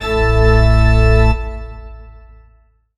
54_34_organ-A.wav